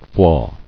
[flaw]